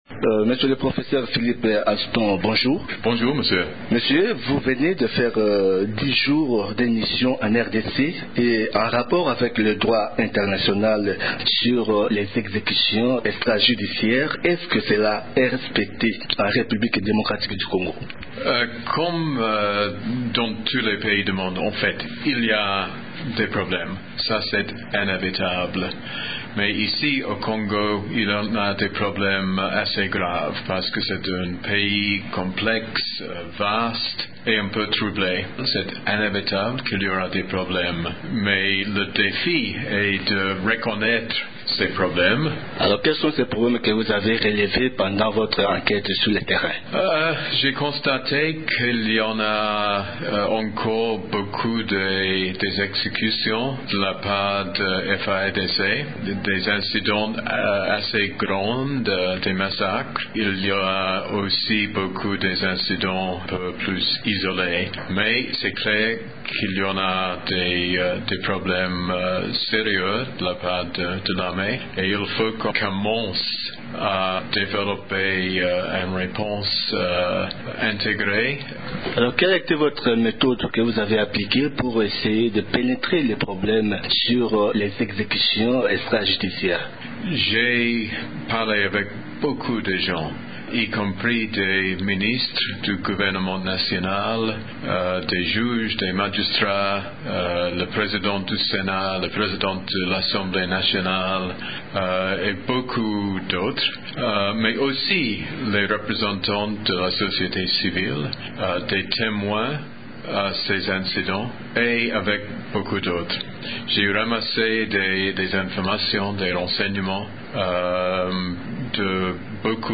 Comment arrêter les massacres des civils au Kivu ? Philip Alston, répond.